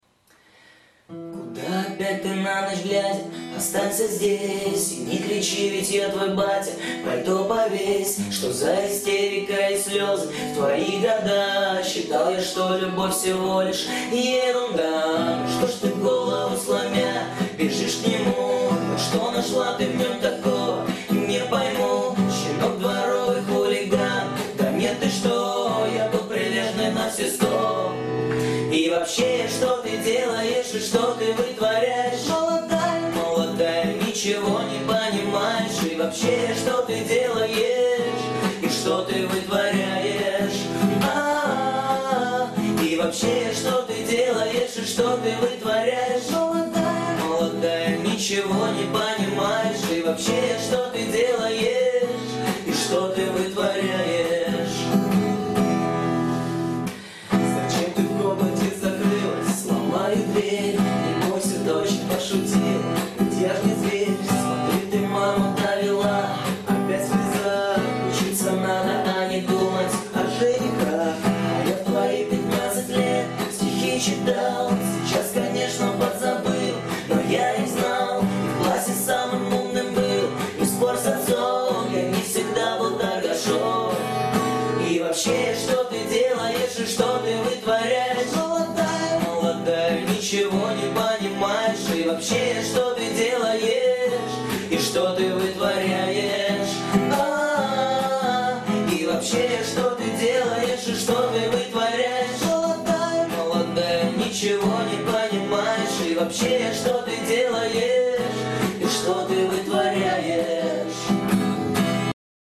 Дворовые под гитару